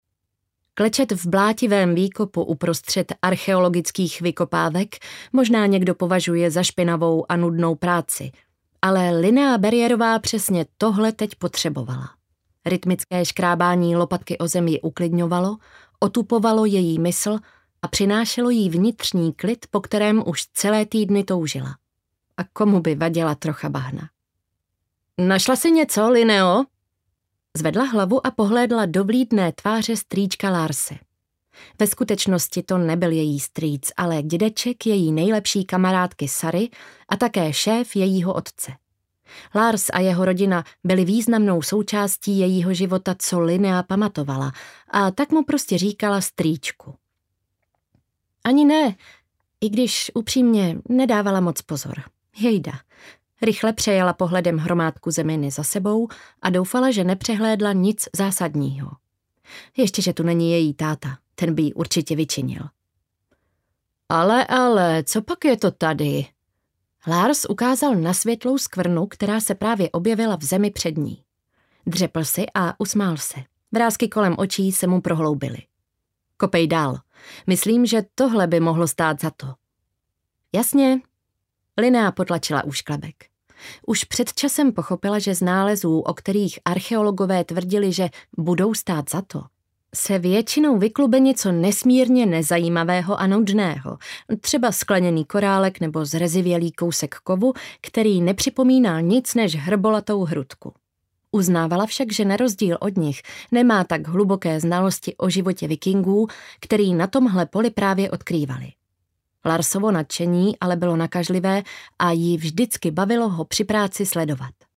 Runy osudu audiokniha
Ukázka z knihy